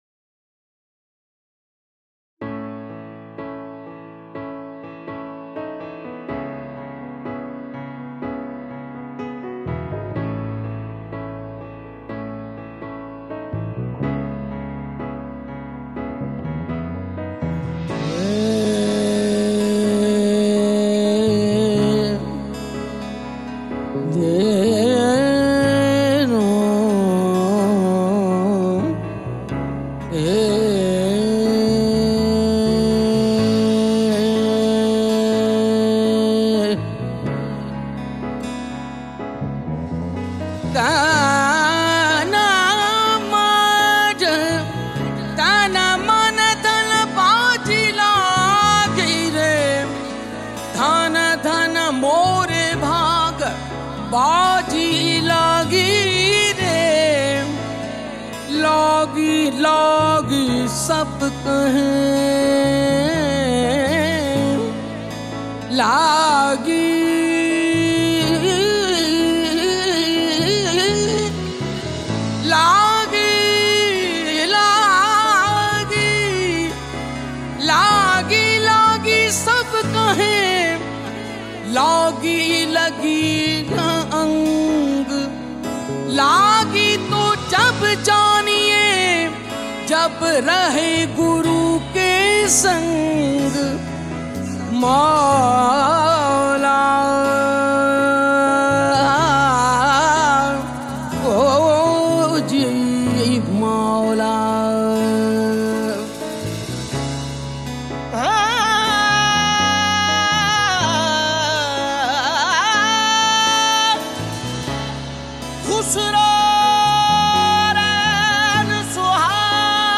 Kalaam/Poetry ,ﺍﺭﺩﻭ urdu , ﭙﻨﺠﺎﺑﻰ punjabi